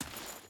Footsteps / Dirt
Dirt Chain Walk 5.wav